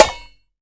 Snares
SNARE.21.NEPT.wav